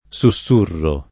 SuSS2rro] (oggi lett. susurro [SuS2rro]) s. m. — es.: uno sbalordimento, un’ansietà, un cruccio, un susurro [